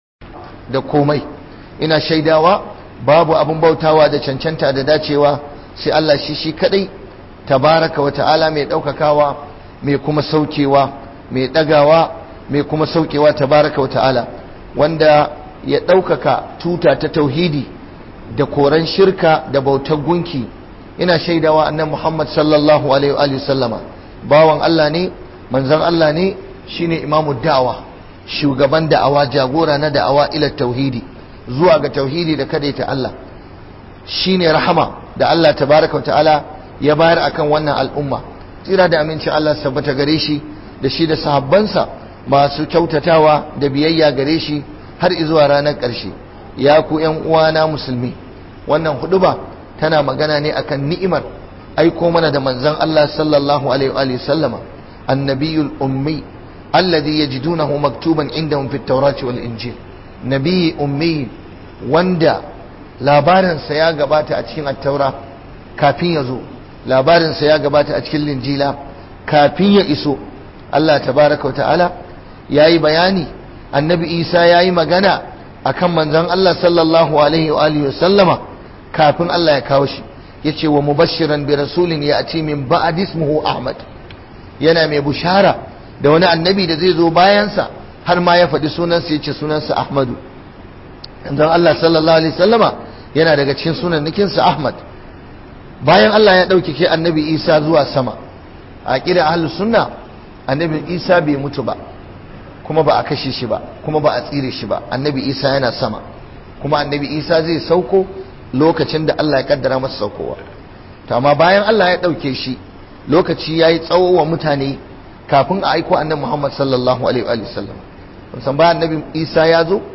NI'IMAR AIKO MANA DA MANZON ALLAH (SAW) - Huduba